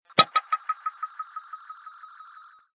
Descarga de Sonidos mp3 Gratis: videojuegos 3.